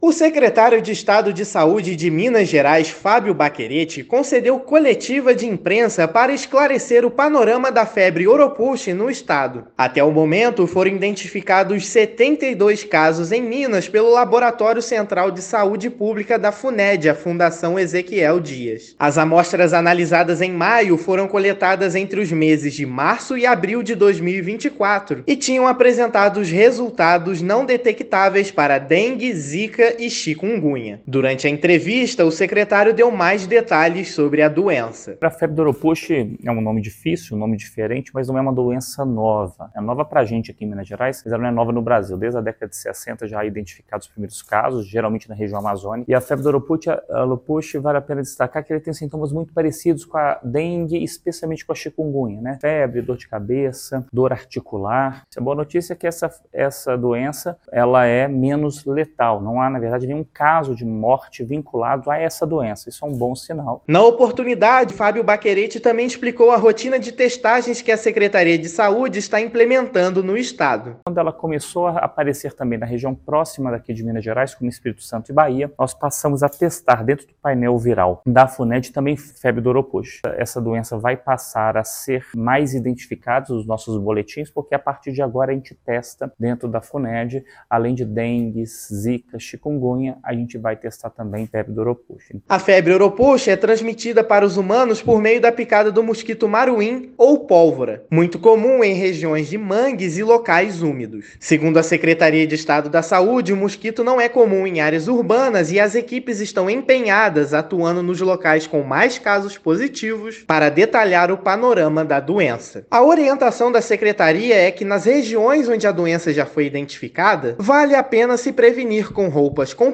Com ampliação da vigilância epidemiológica das arboviroses que circulam no estado, 72 casos da doença já foram identificados. Ouça a matéria de rádio: